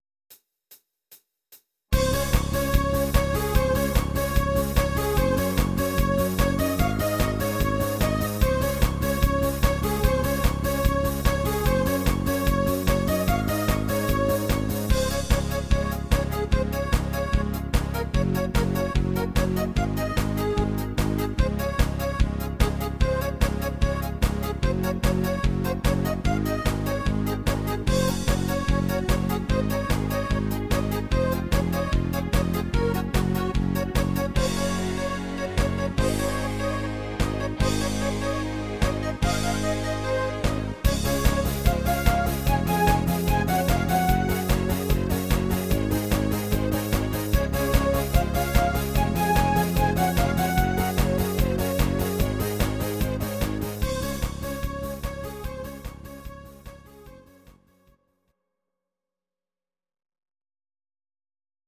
Faschingshit aus dem Badner Land) cover